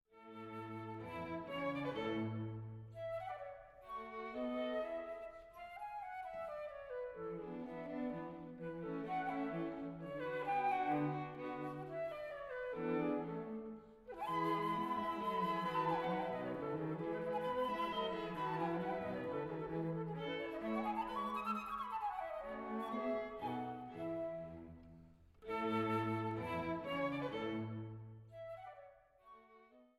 Neue Quartette für Flöte und Streichtrio
Die CD wurde im Dezember 1999 in Paris aufgenommen.